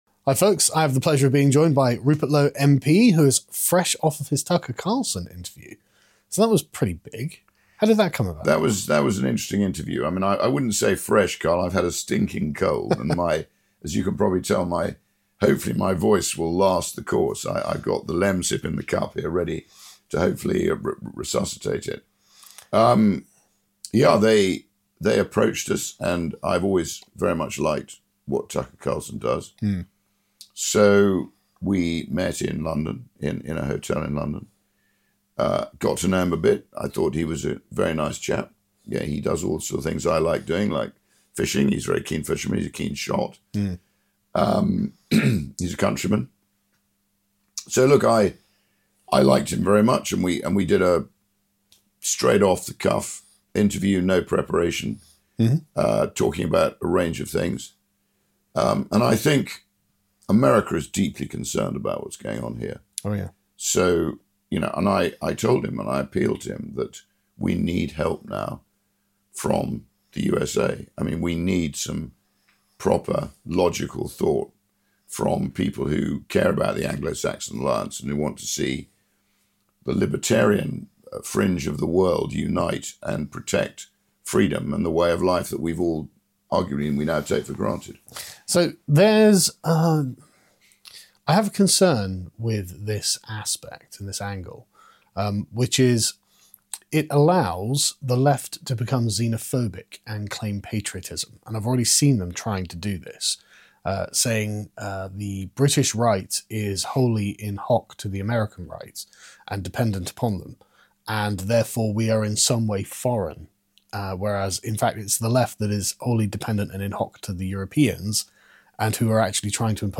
'The British Need to Stand Up' | Interview with Rupert Lowe